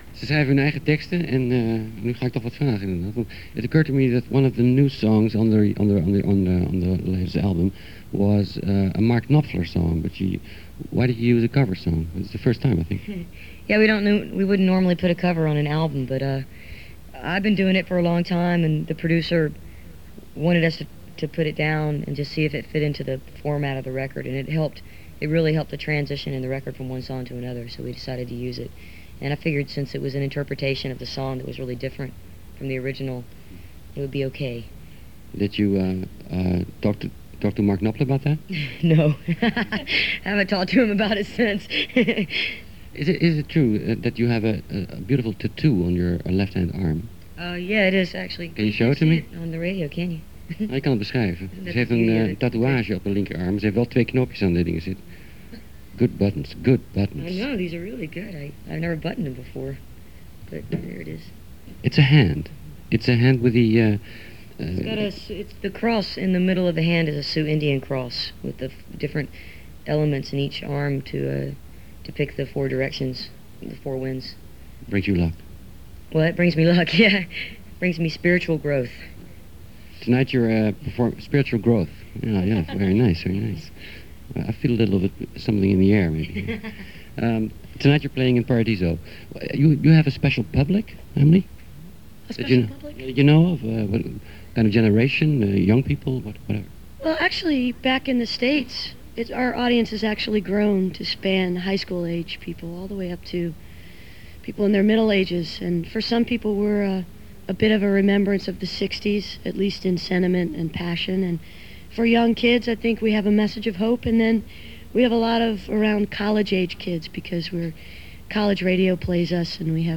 (acoustic duo)
05. interview (2:56)